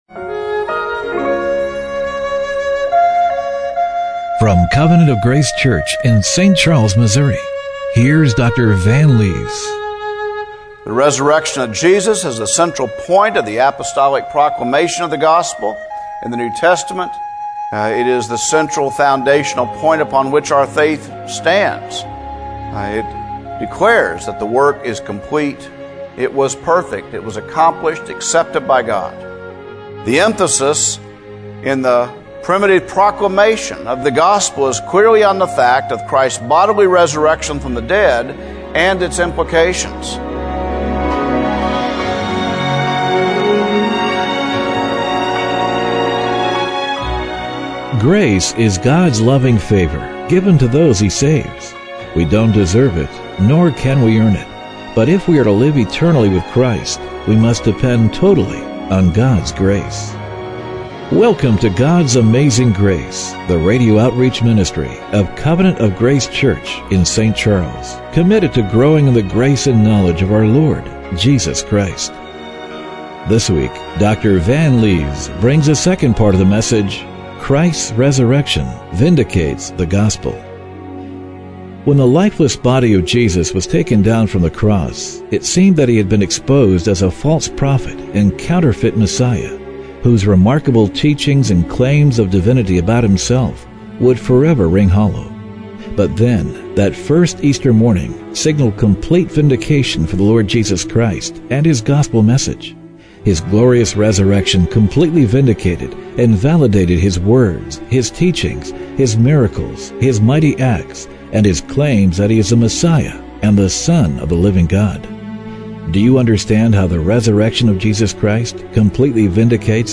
Service Type: Radio Broadcast